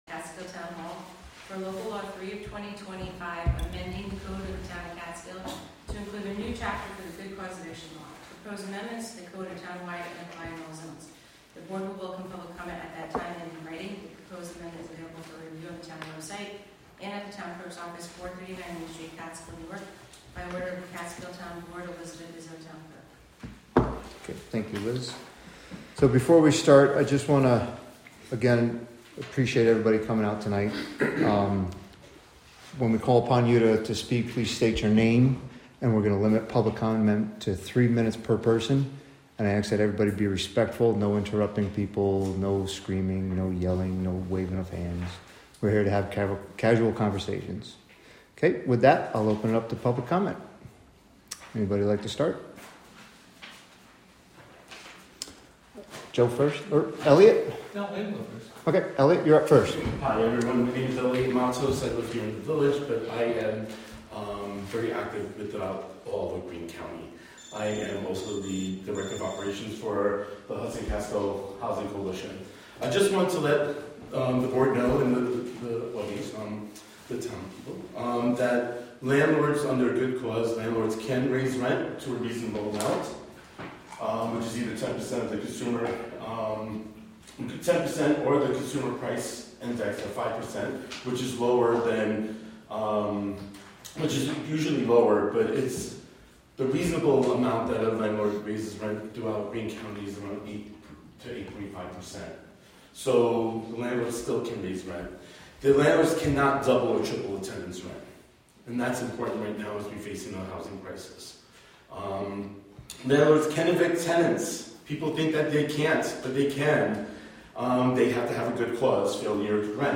Live from the Town of Catskill: May 6, 2025 Catskill Town Board Meeting (Audio)